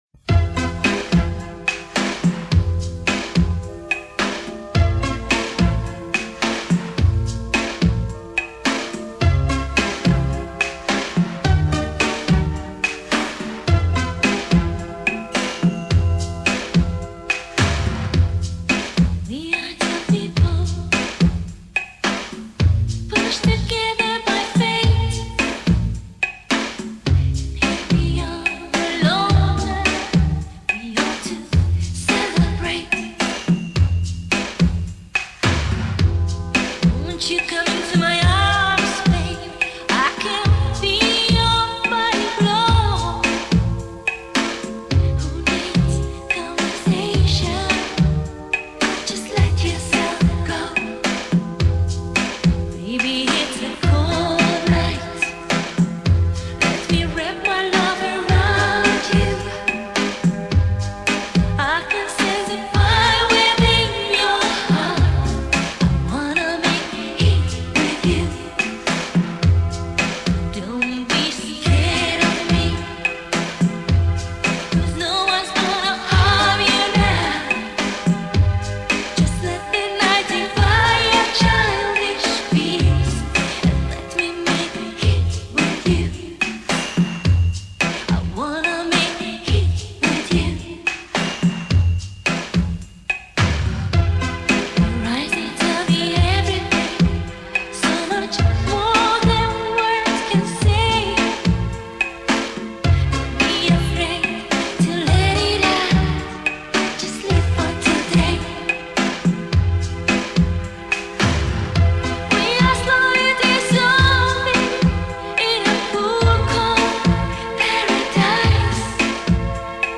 подавлен гул.